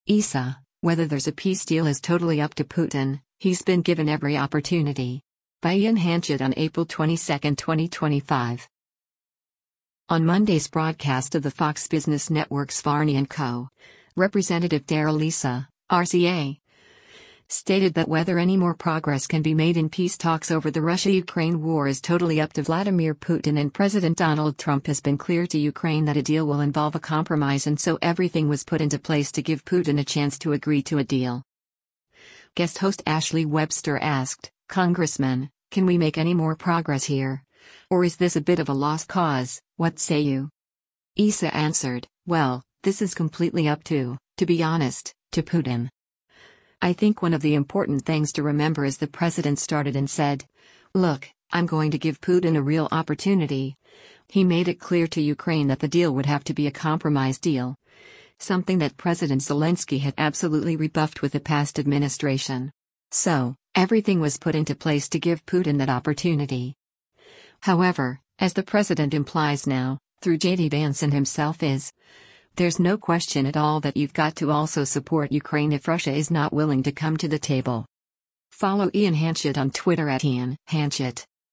On Monday’s broadcast of the Fox Business Network’s “Varney & Co.,” Rep. Darrell Issa (R-CA) stated that whether any more progress can be made in peace talks over the Russia-Ukraine war is totally up to Vladimir Putin and President Donald Trump has been clear to Ukraine that a deal will involve a compromise and so “everything was put into place to give Putin” a chance to agree to a deal.